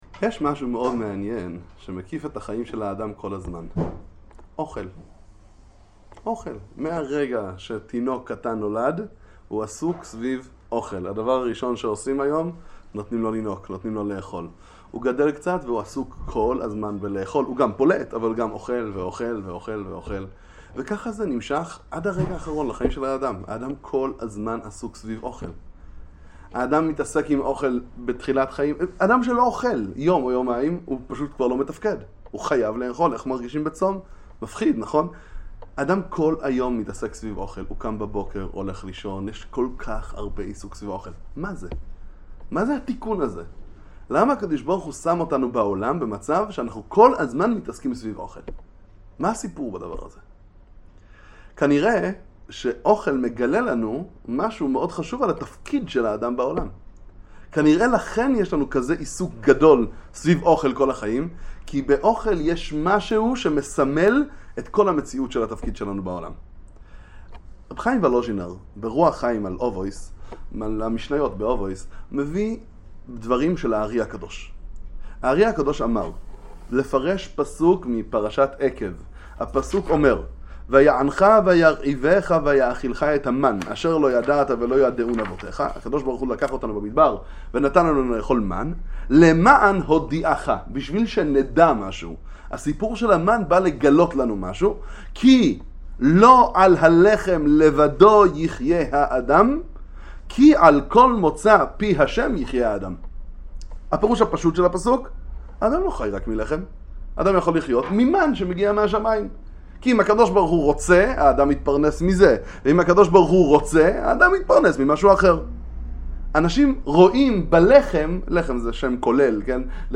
דבר תורה קצר לפרשת עקב.